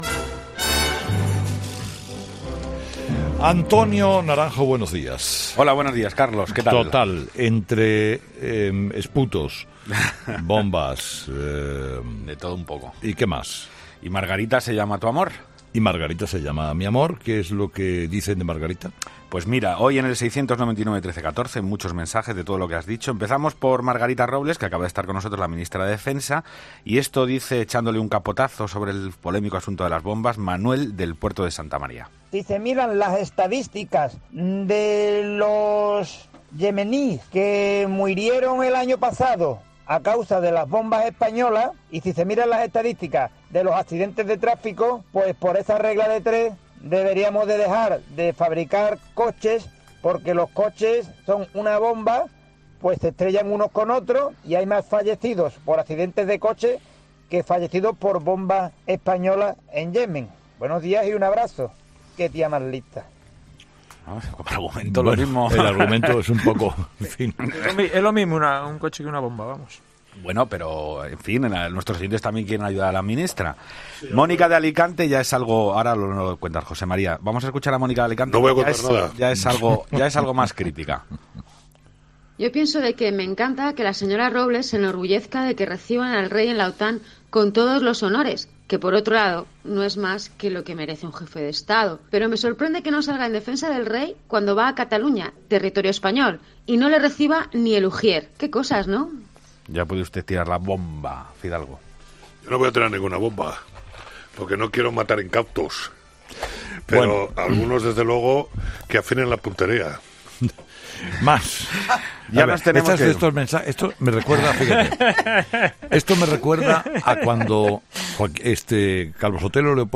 Un día más, los oyentes han analizado con mordaz ironía la actualidad. De nuevo, protagonismo para el doctor Sánchez con permiso, eso sí, de Rufián.